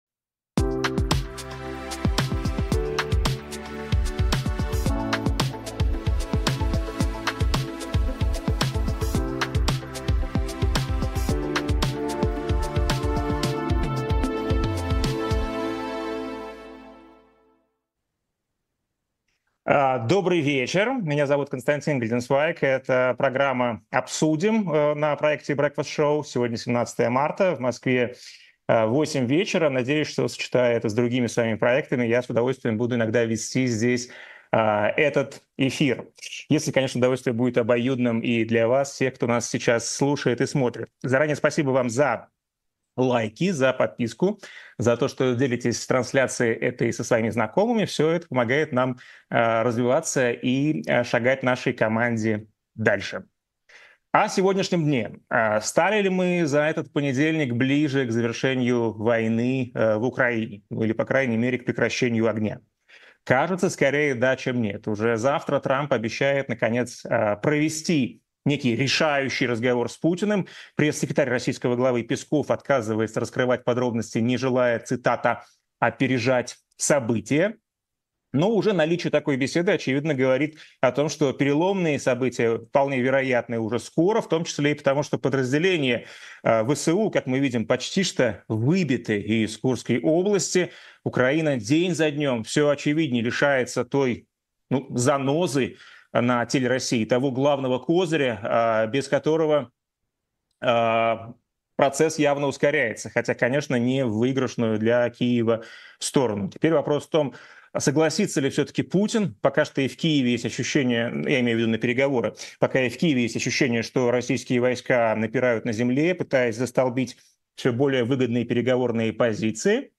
В программе «Обсудим» мы говорим о самых важных событиях с нашими гостями. Гость сегодняшнего выпуска — правозащитник Олег Орлов, и с ним мы обсудим, чего ждать от разговора Путина и Трампа, как выглядит выгодная позиция для Киева и почему США не хотят расследовать военные преступления РФ в Украине.